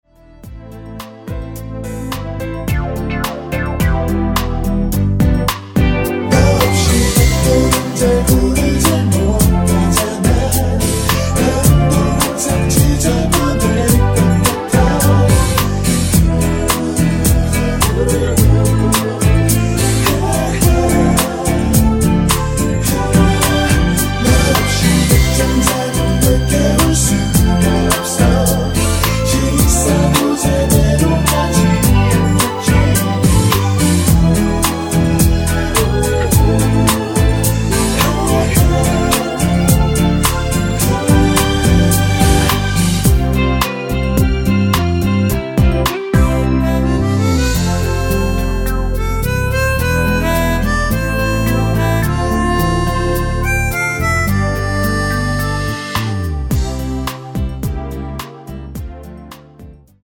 코러스 포함된 MR 입니다.(미리듣기 참조)
Bb
◈ 곡명 옆 (-1)은 반음 내림, (+1)은 반음 올림 입니다.
앞부분30초, 뒷부분30초씩 편집해서 올려 드리고 있습니다.
중간에 음이 끈어지고 다시 나오는 이유는